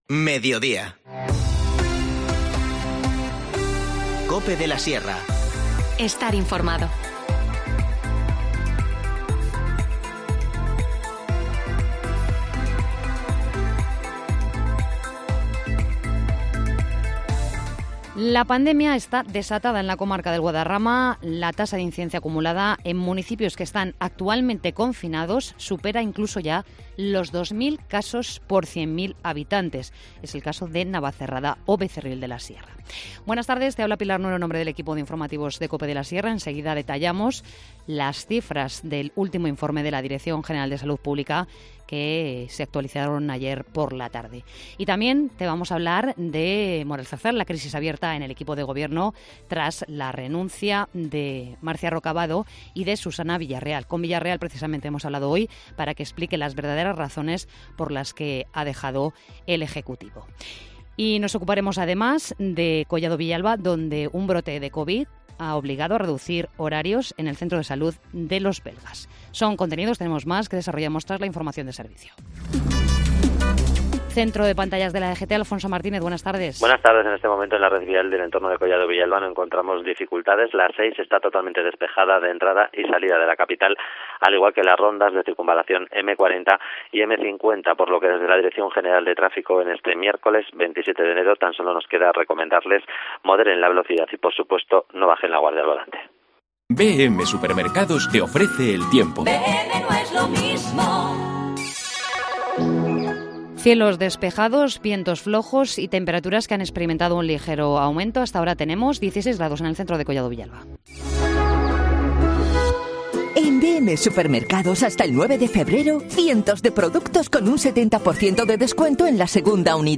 Informativo Mediodía 27 de enero